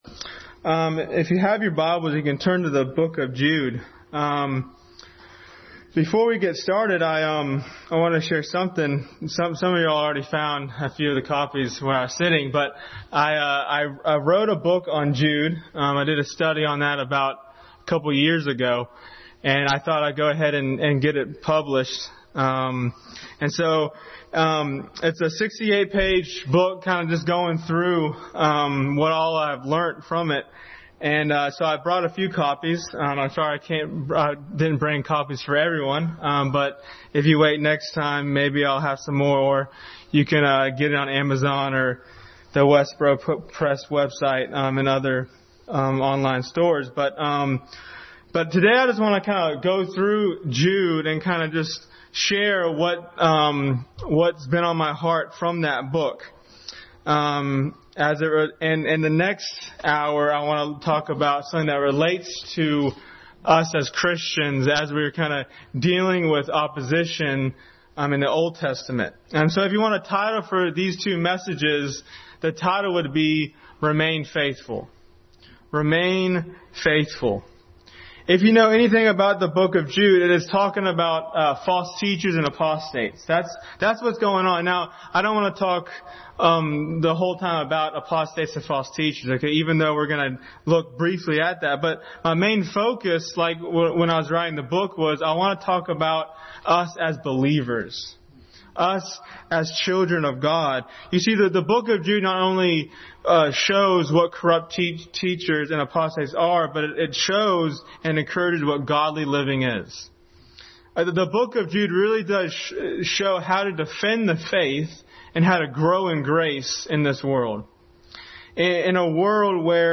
Remain Faithful Passage: Jude 1-25, Colossians 2:6-10, Ephesians 6:18, Romans 8:26, Matthew 6:10, Galatians 6:9 Service Type: Sunday School